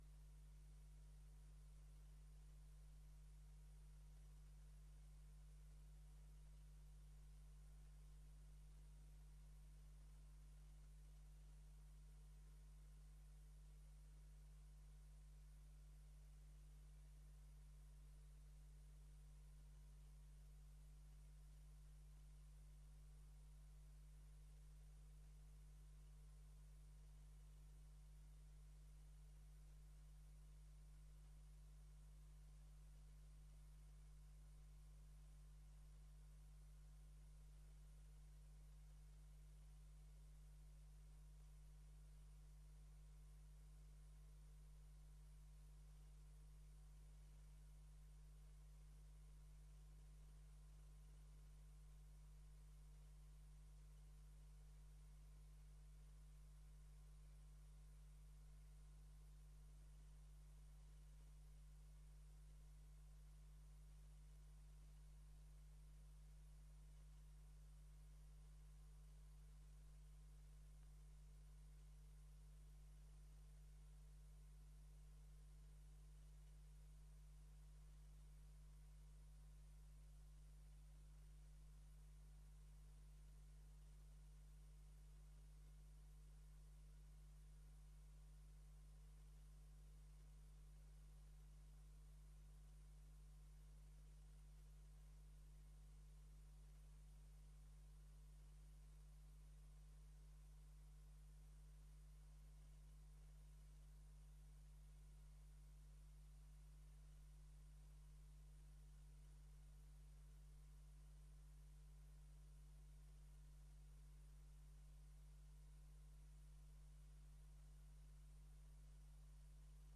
Gemeenteraad 21 mei 2024 20:30:00, Gemeente Dalfsen
Locatie: Raadzaal